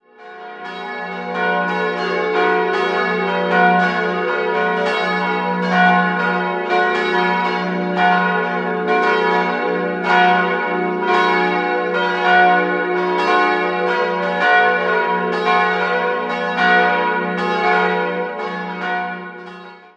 4-stimmiges Geläut: fis'-gis'-h'-cis''